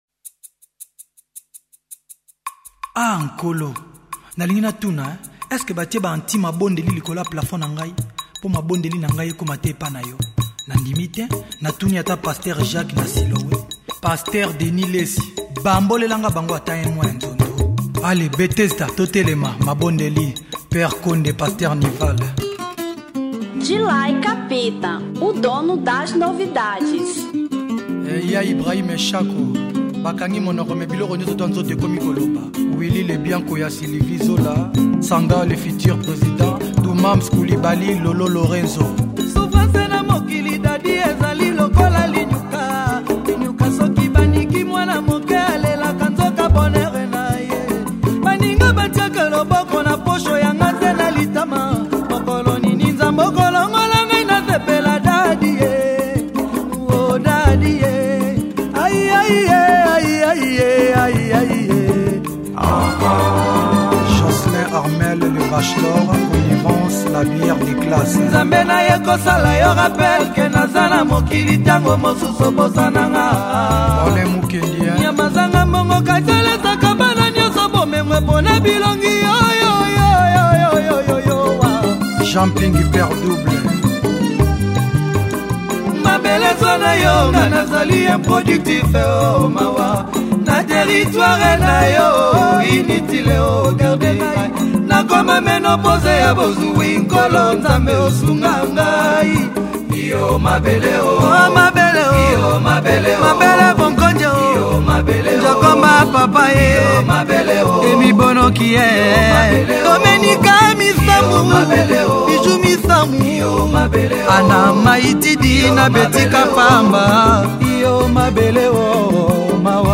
Rumba 2006